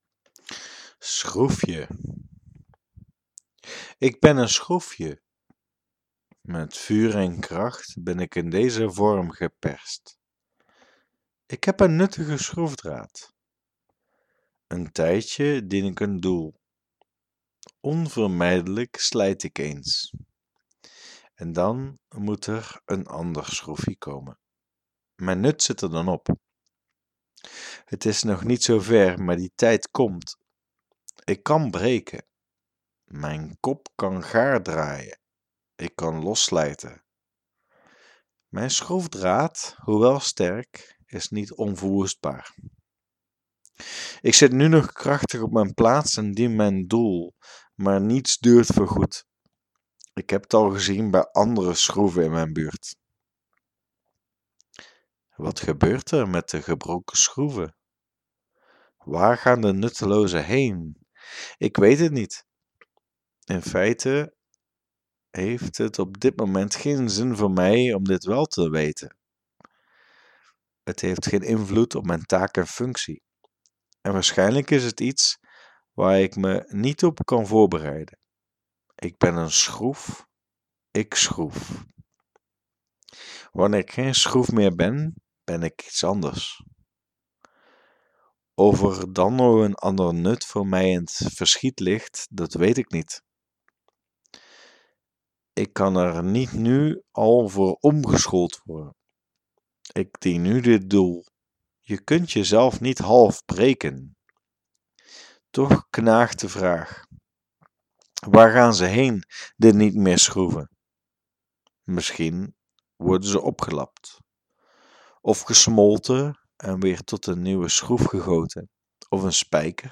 Audio stories Korte proza